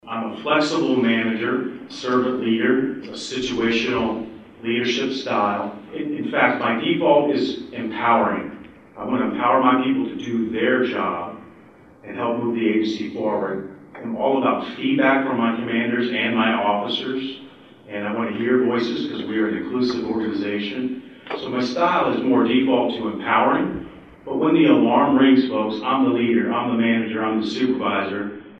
The Riley County Law Board held a three hour public forum at the Manhattan Fire Department Headquarters.